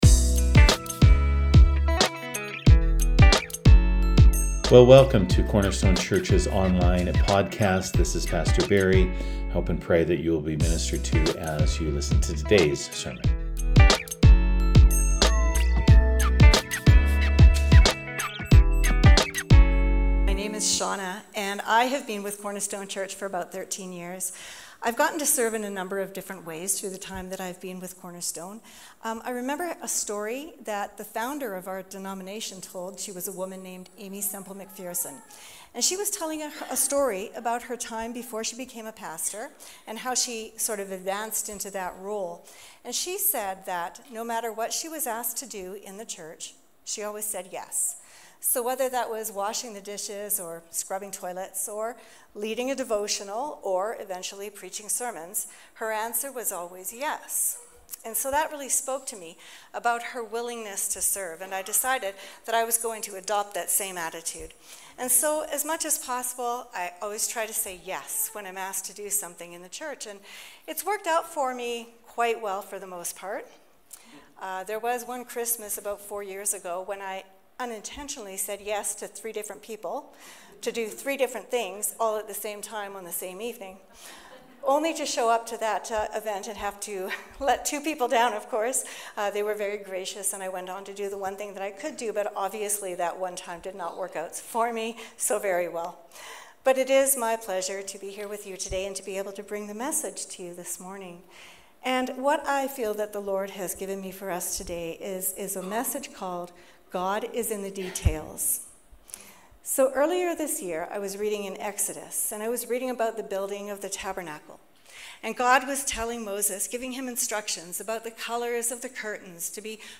Sermons | Cornerstone Church